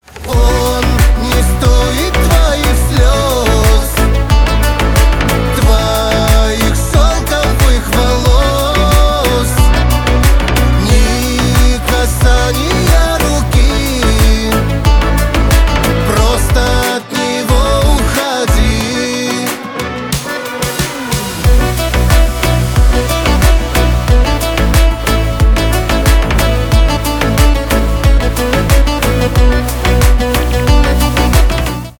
поп
кавказские , танцевальные